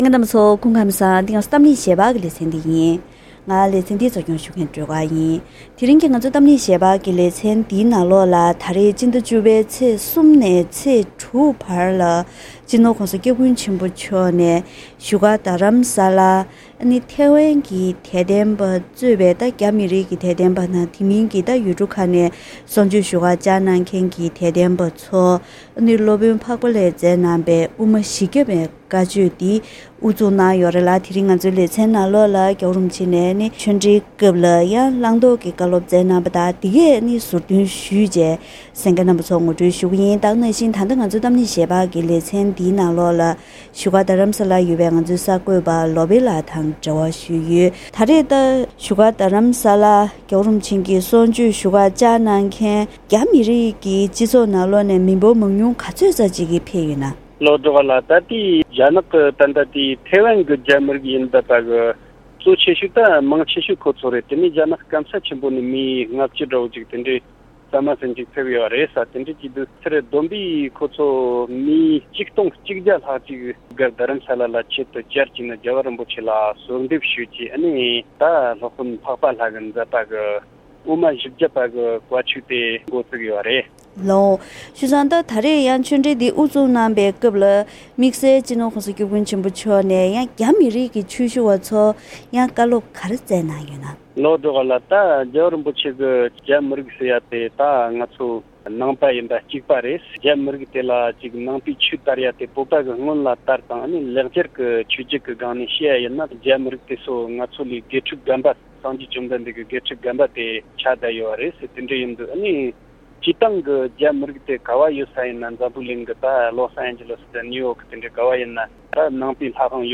དེ་རིང་གི་གཏམ་གླེང་ཞལ་པར་ལེ་ཚན་ནང་སྤྱི་ནོར་༧གོང་ས་སྐྱབས་མགོན་ཆེན་པོ་མཆོག་ནས་ཐེ་ཝན་གྱི་རྒྱ་མི་གཙོས་པའི་དད་ལྡན་ཆོས་ཞུ་བ་ཚོར་སློབ་དཔོན་འཕགས་པ་ལྷས་མཛད་པའི་དབུ་མ་བཞི་བརྒྱ་པའི་བཀའ་ཆོས་གནང་ཡོད་པའི་ཁྲོད་ནས་ཉིན་དང་པོ་དང་ཉིན་གཉིས་པའི་གསུང་ཆོས་སྐབས་གནང་བའི་བཀའ་སློབ་ཁག་ཟུར་བཏོན་ཞུས་པ་ཞིག་གསན་རོགས་གནང་།